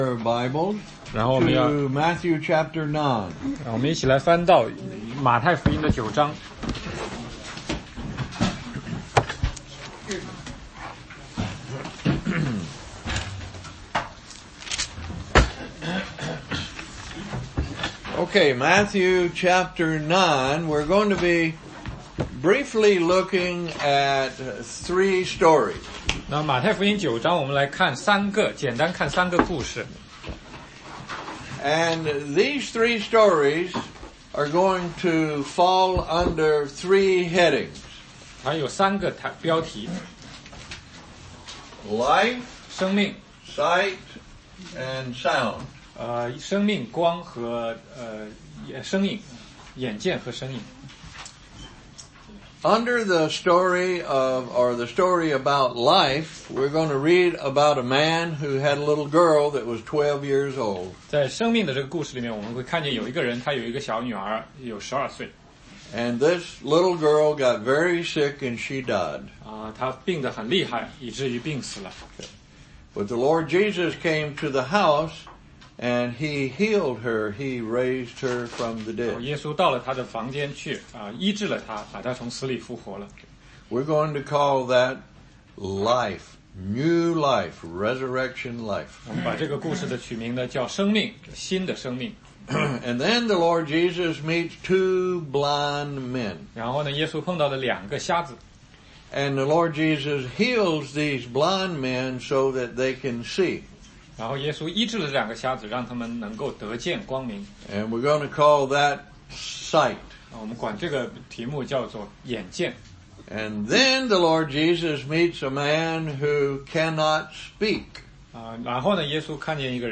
16街讲道录音